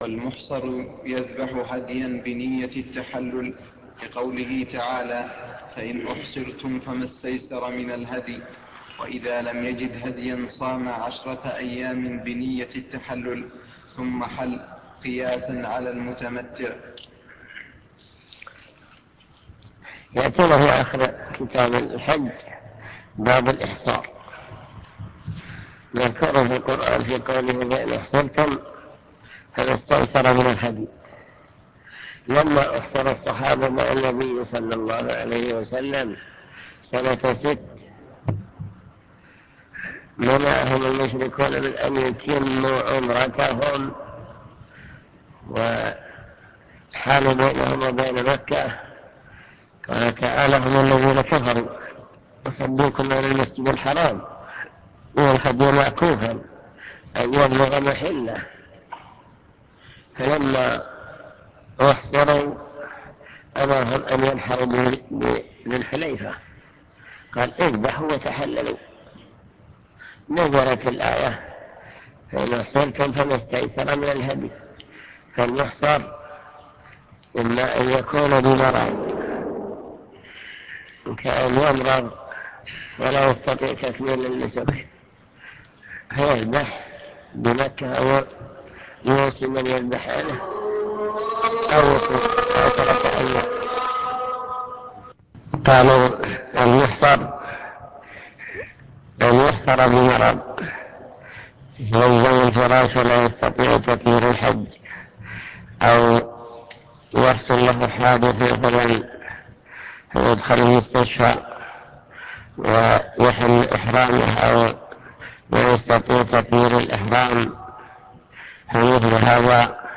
المكتبة الصوتية  تسجيلات - كتب  كتاب الروض المربع الجزء الثاني باب الفدية موجبات الفدية